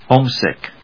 音節home・sick 発音記号・読み方
/hóʊmsìk(米国英語), hˈəʊmsìk(英国英語)/